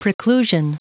Prononciation du mot preclusion en anglais (fichier audio)
preclusion.wav